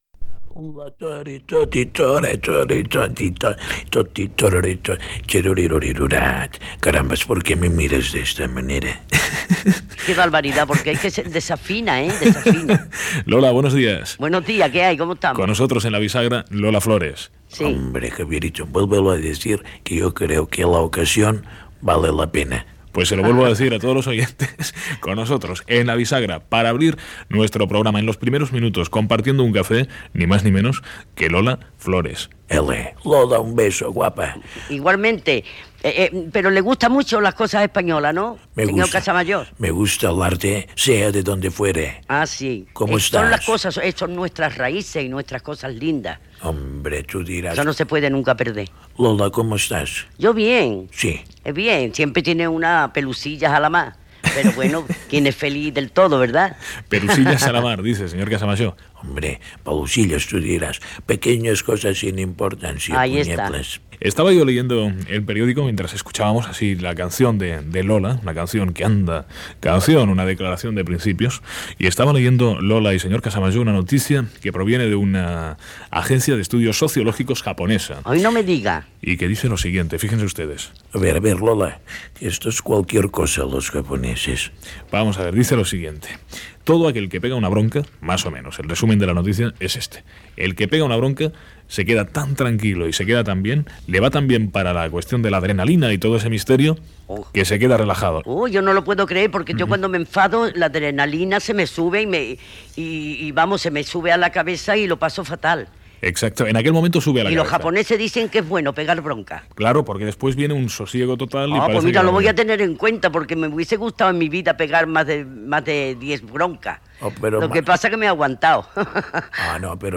Entrevista a la cantant Lola Flores i indicatiu del programa